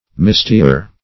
(m[i^]st"[i^]*[~e]r); superl. Mistiest.] [AS. mistig. See